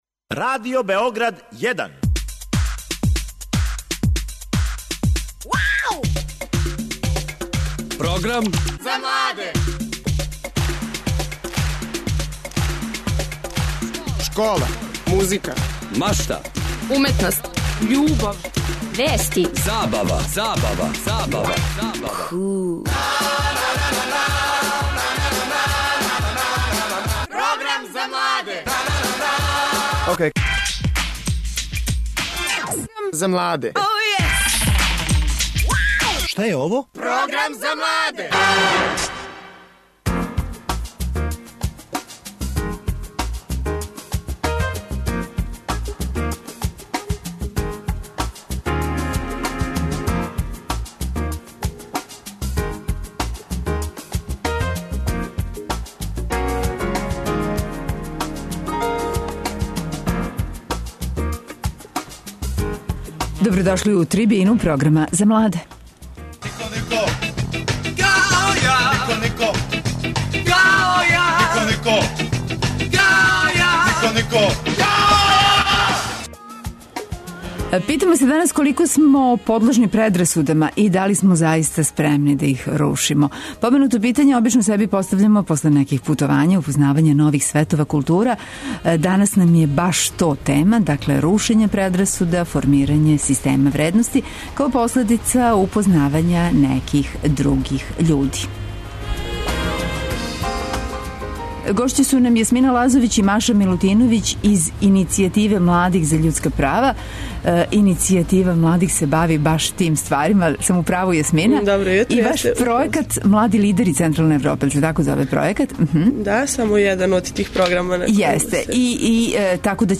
Они су провели 3 недеље у Америци, а сада очекују своје домаћине да им дођу у госте. У емисији ћемо чути утиске младих који су били део овог путовања.